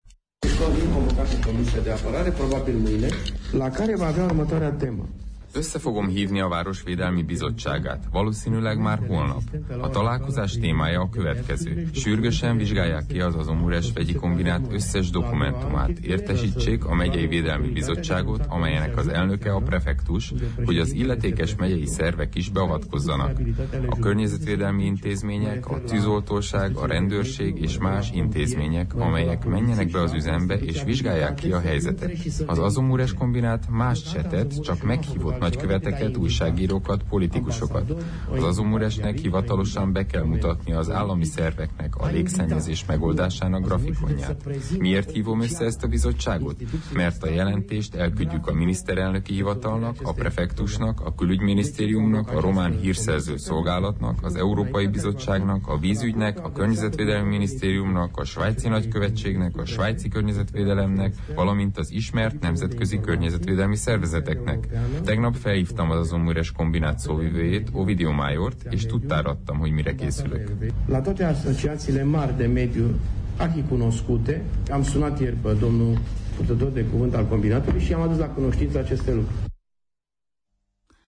Dorin Florea mai sajtótájékoztatóján elmondta, ezzel a szakbizottsággal vizsgáltatná ki az Azomaros vegyi kombinát által okozott légszennyezést.